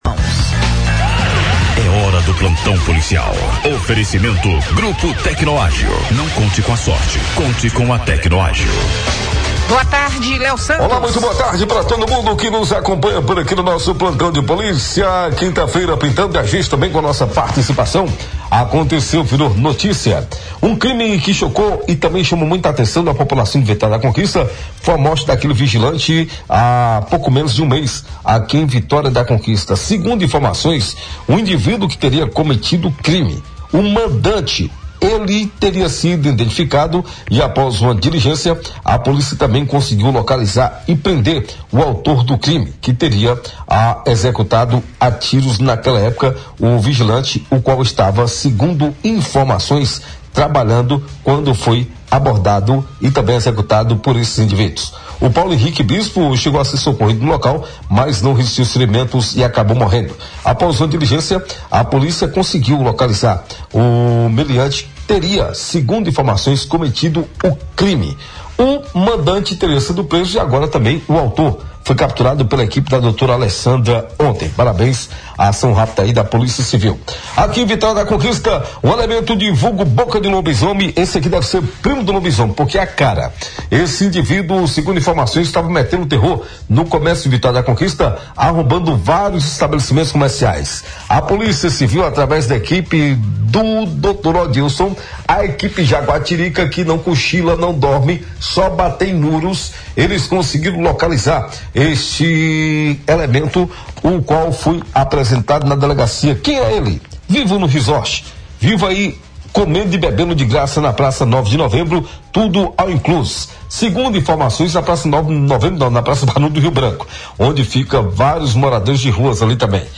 O Plantão Policial do Sudoeste Agora, programa da Rádio Clube de Conquista, nesta quinta-feira (31) apresentou um panorama das ocorrências registradas nas últimas 24 horas em Vitória da Conquista. Entre os principais destaques, estão as prisões do mandante e do atirador envolvidos na morte de um vigilante, crime ocorrido na Zona Oeste da cidade. A captura de um arrombador e outros fatos também marcaram o noticiário policial.